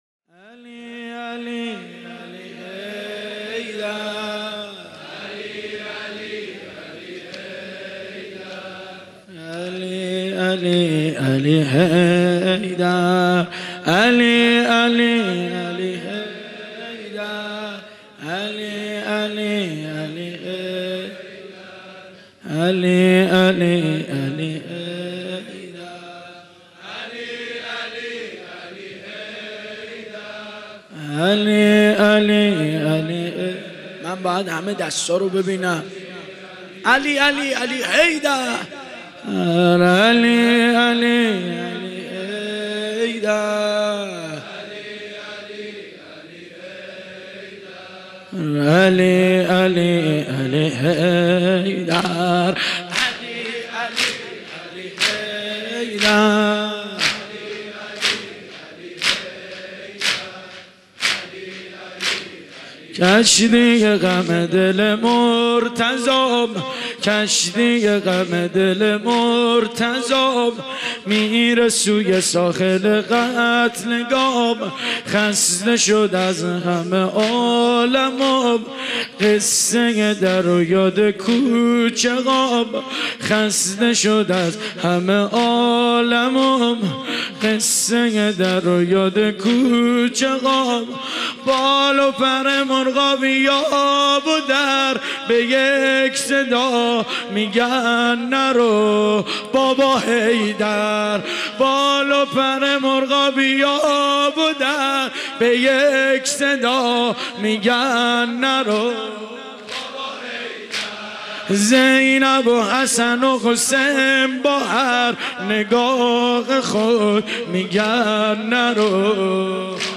مناسبت : شب نوزدهم رمضان - شب قدر اول
قالب : زمینه